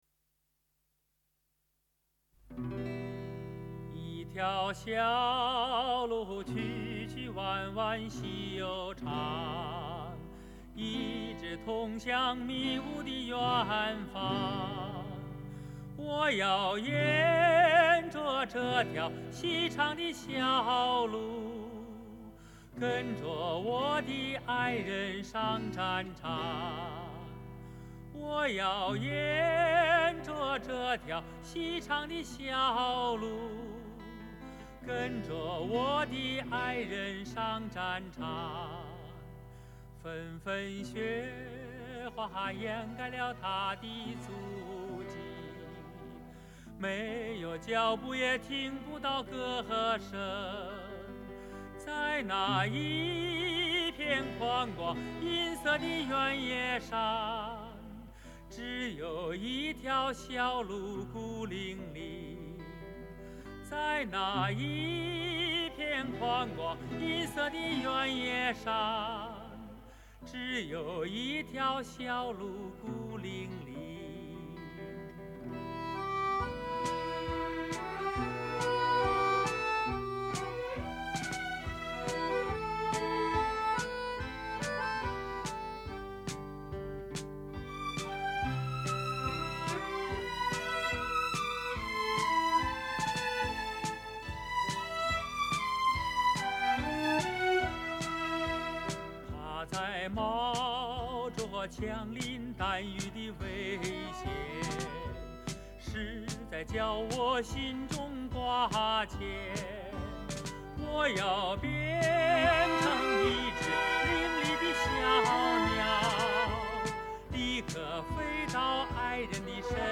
俄罗斯歌曲